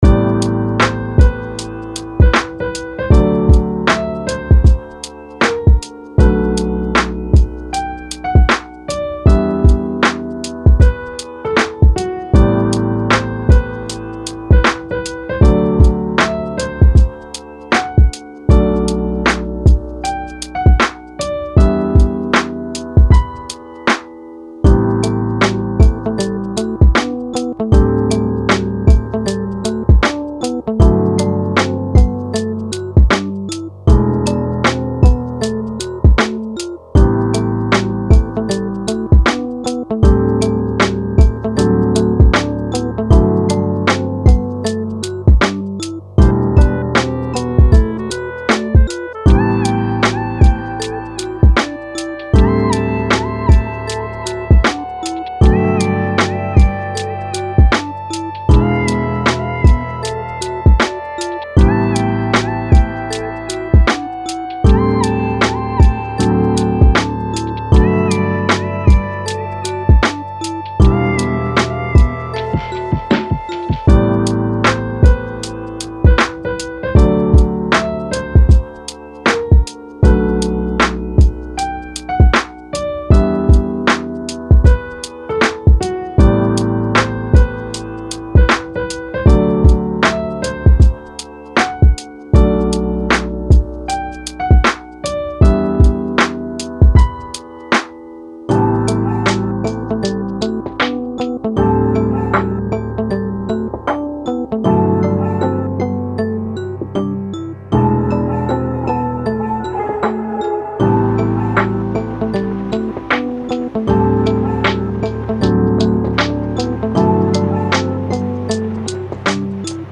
おしゃれ かわいい しっとり
Download 10分ループVer.